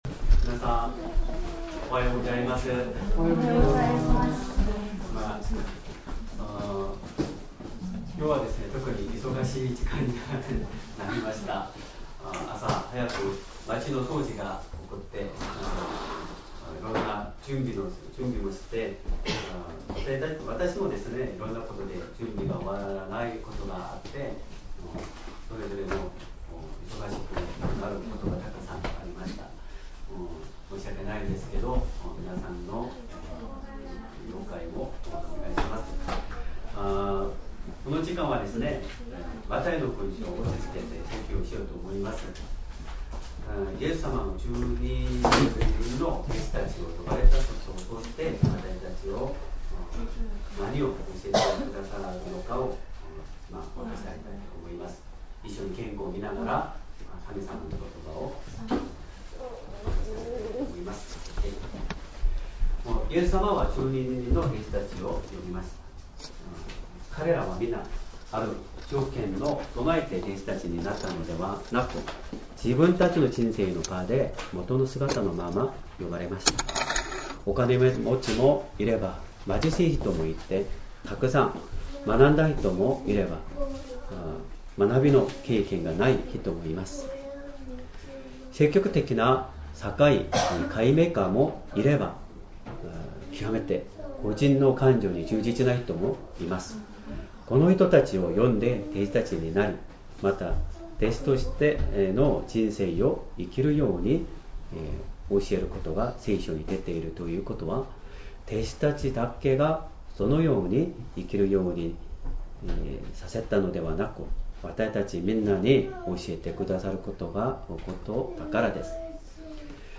Sermon
2025年6月1日 主日礼拝 説教 「主の弟子たちの歩む道 」 聖書 マタイの福音書 10章1-20節 10:1 イエスは十二弟子を呼んで、汚れた霊どもを制する権威をお授けになった。